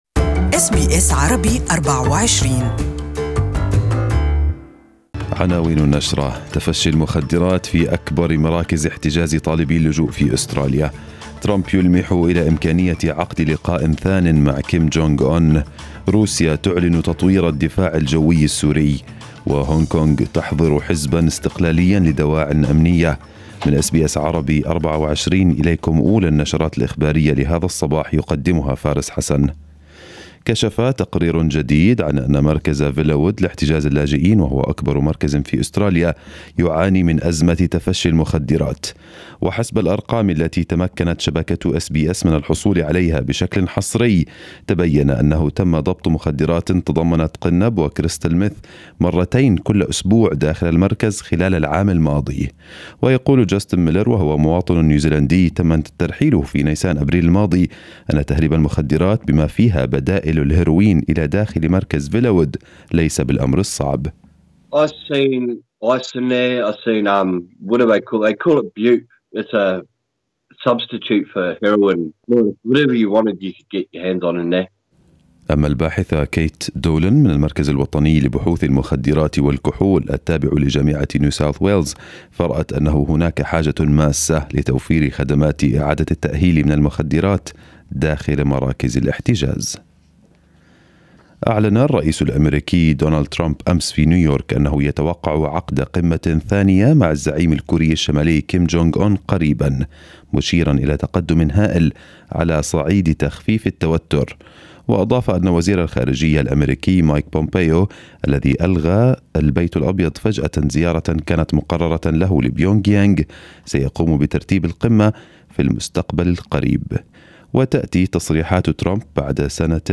نشرة الأخبار المفصلة الأولى لهذا الصباح